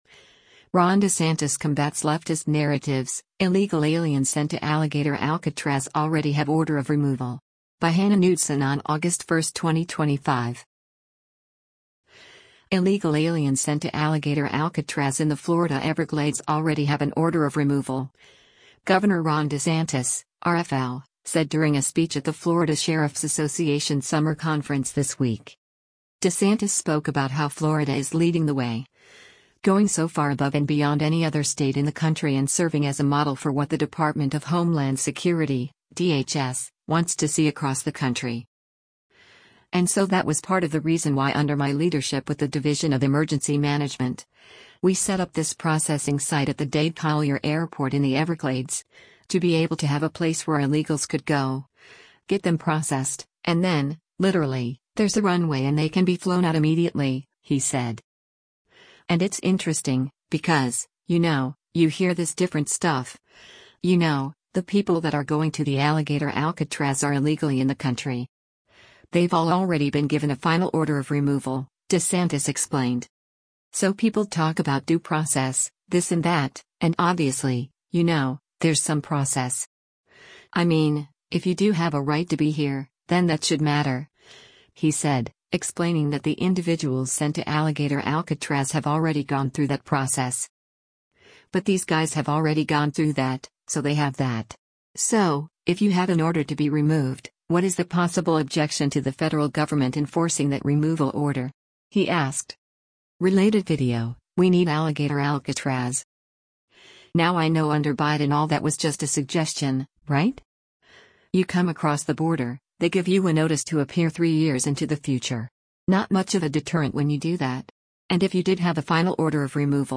Illegal aliens sent to Alligator Alcatraz in the Florida Everglades already have an order of removal, Gov. Ron DeSantis (R-FL) said during a speech at the Florida Sheriffs Association Summer Conference this week.